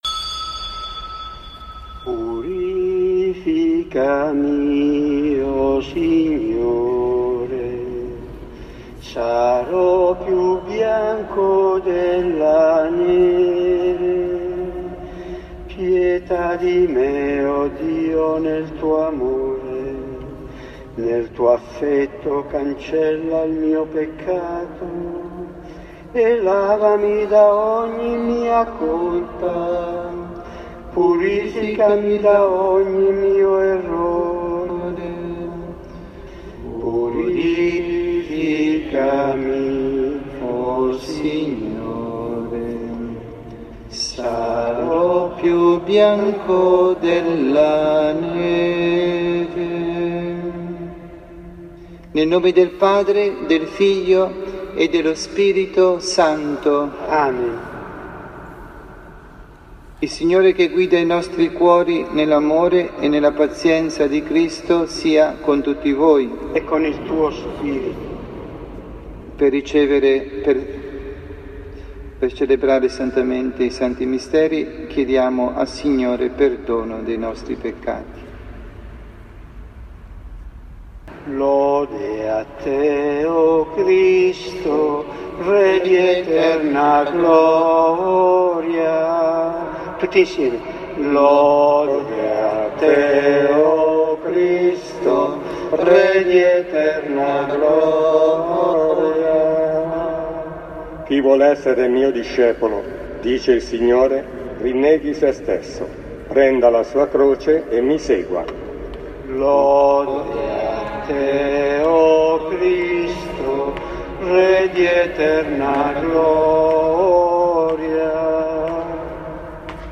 Omelia
dalla Parrocchia S. Rita, Milano